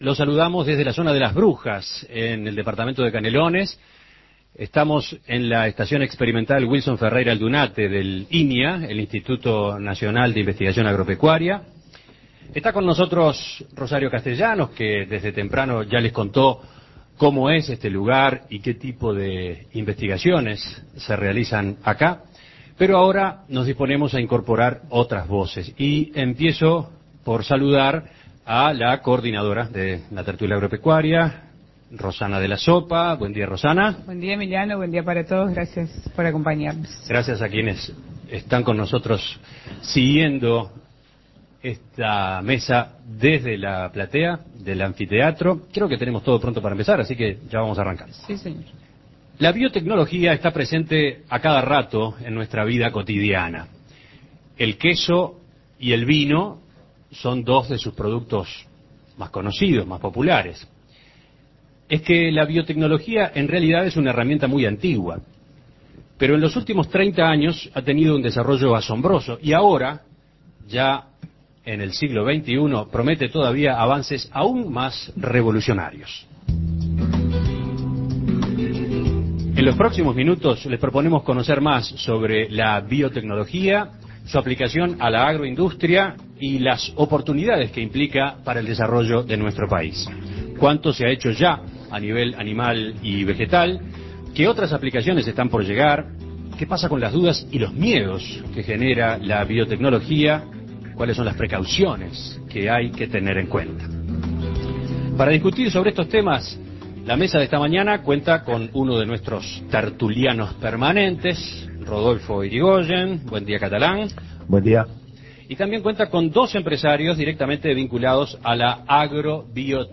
la Tertulia Agropecuaria se trasladó hasta la Estación Experimental Wilson Ferreira Aldunate, del INIA, en la zona de Las Brujas, Canelones